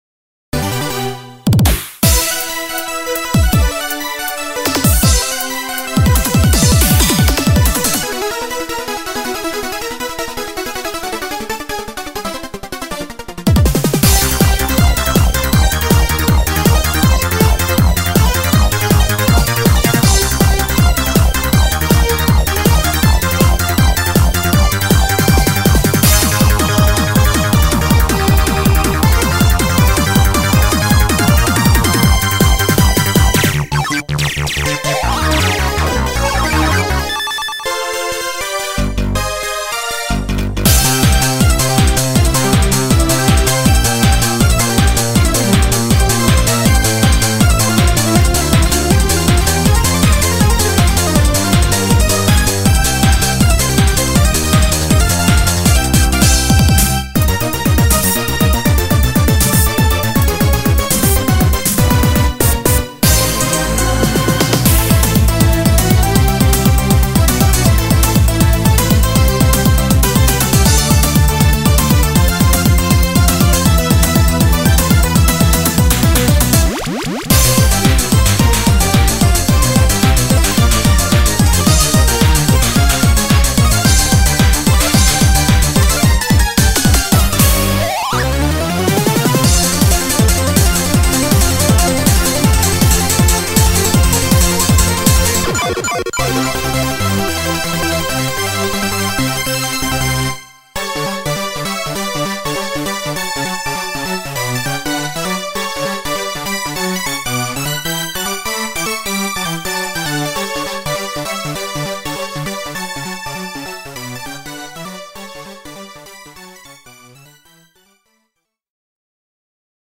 BPM160-220
Audio QualityPerfect (Low Quality)